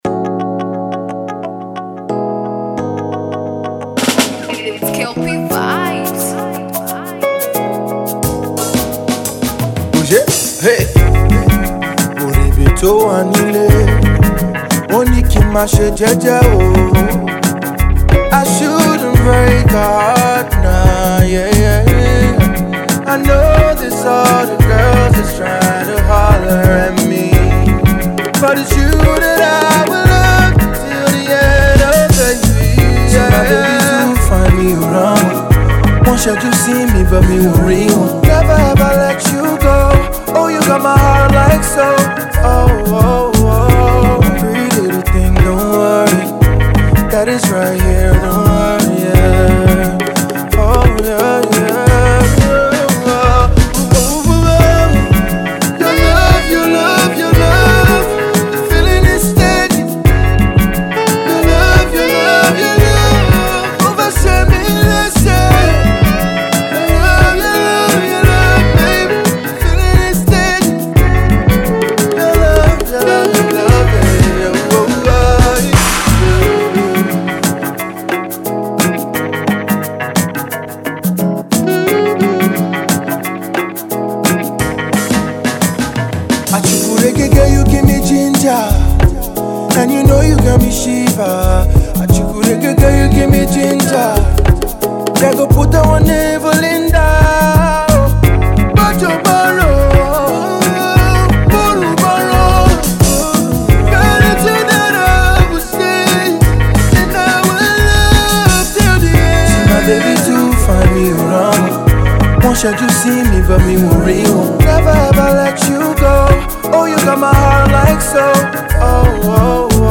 mellow Afro Soul tune